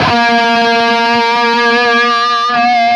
LEAD A#2 CUT.wav